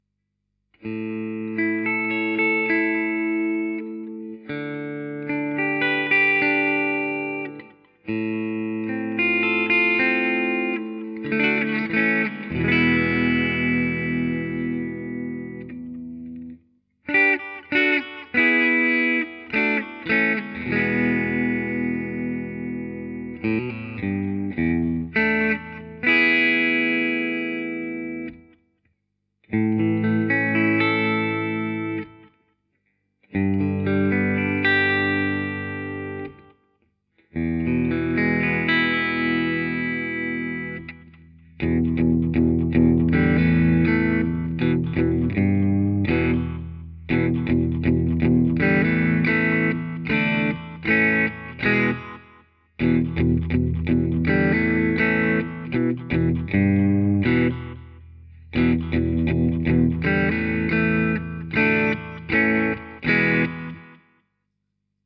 The Behemoth is extremely loud but with a distinctly vintage voice.
It is strong in the upper mid/lower treble area and has a huge bass.
Listen here:     Bridge Full